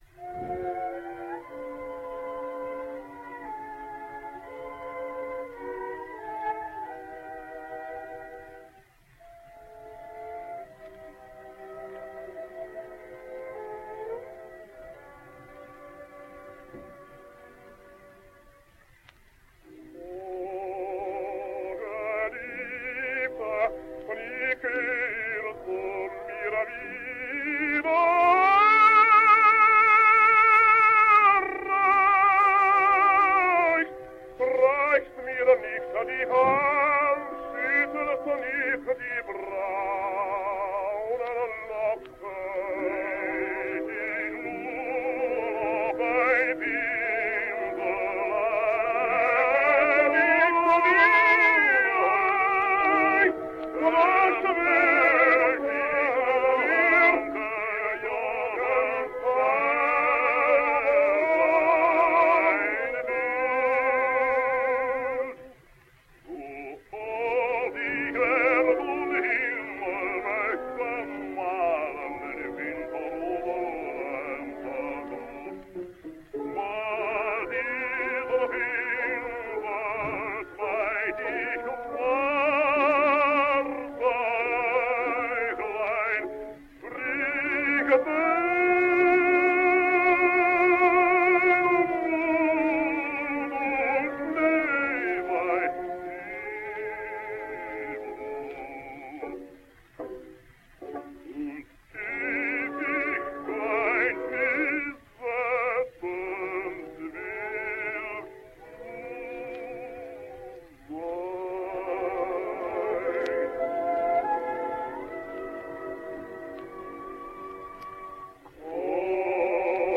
Italian Tenor